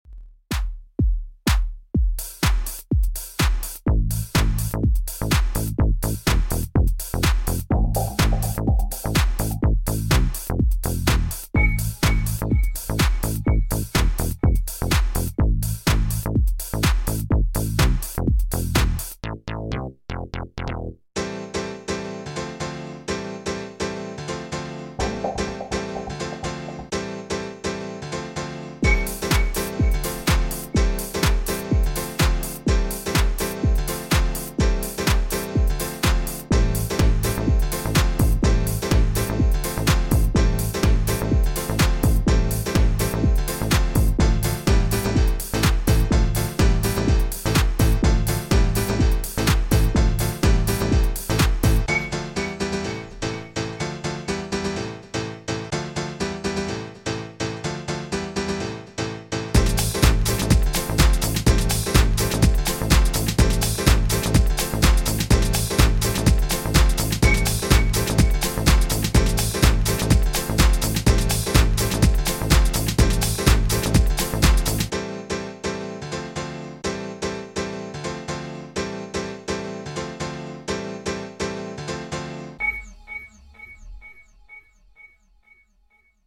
• Tempo: 125 BPM.